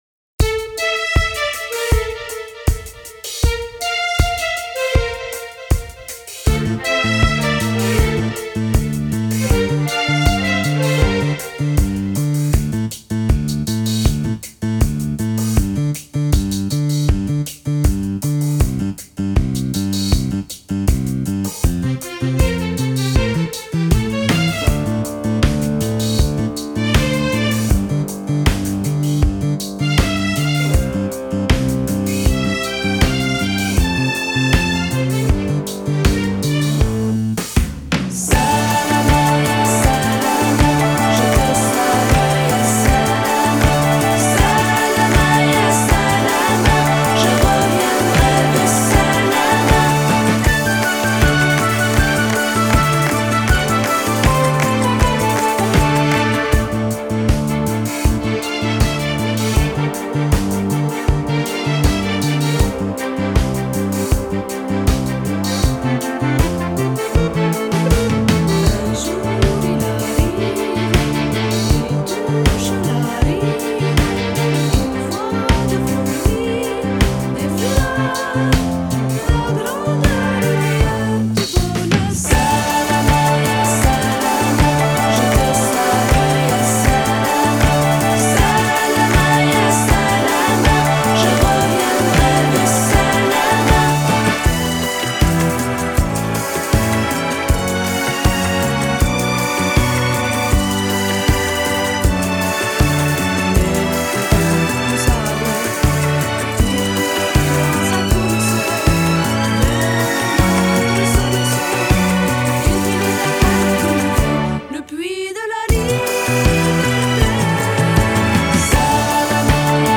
Salma ya salama (instrumental).mp3 - Petit Fichier